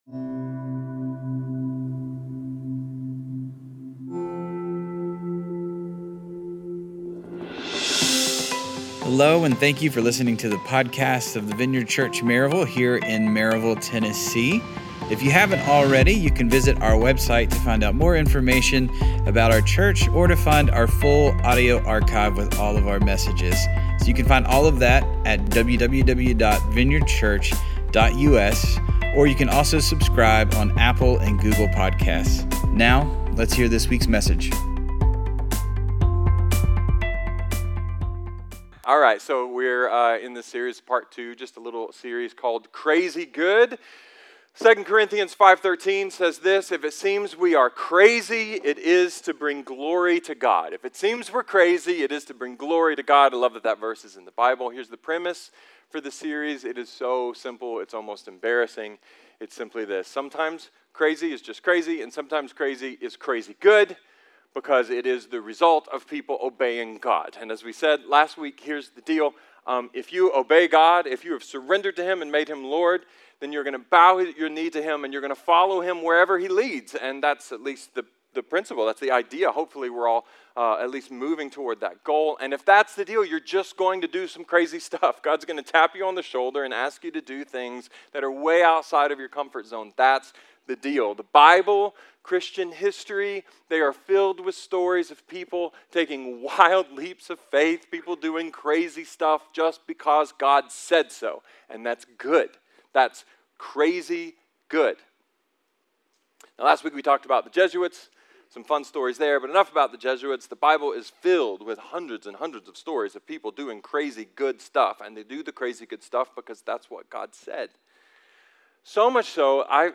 A sermon about heroism, hope, and the quiet crazy that changes the world.